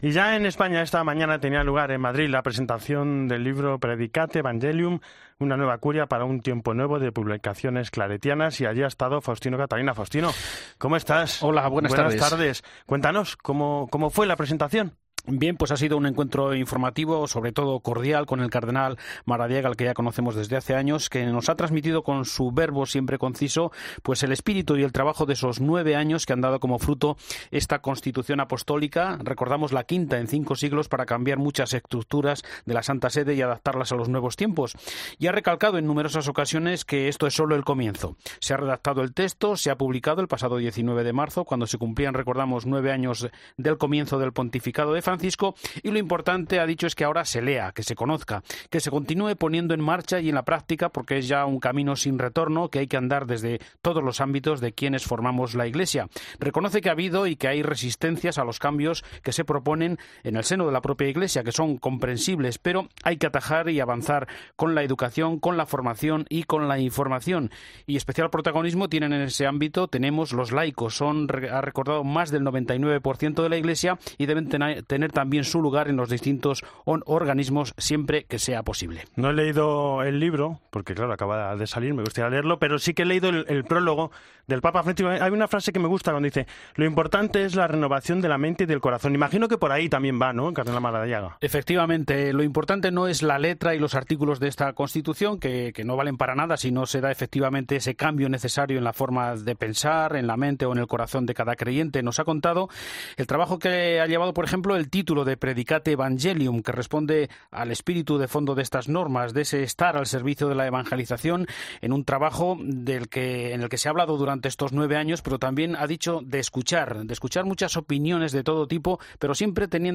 Así lo indica el cardenal Óscar Madariaga, arzobispo de Tegucigalpa (Honduras) y coordinador del Consejo de cardenales, en diálogo con la prensa española este sábado.